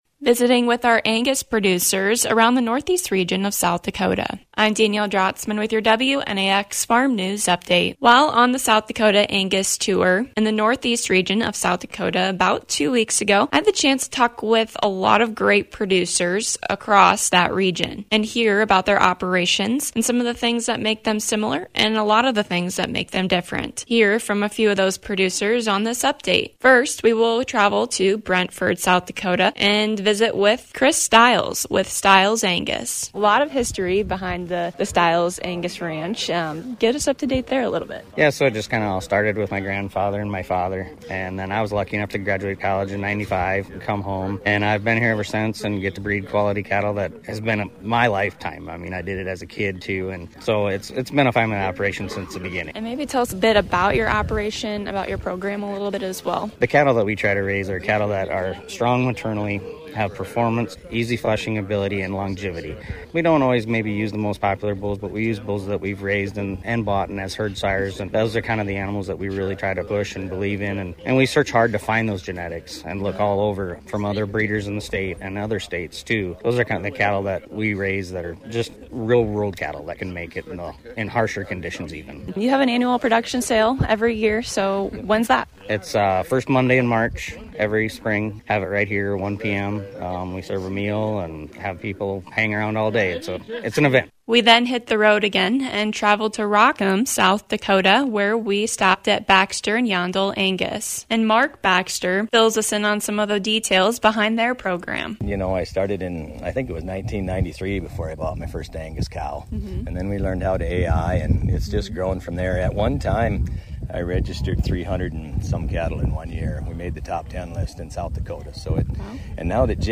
Visiting with producers all over the NE region on the SD Angus Tour.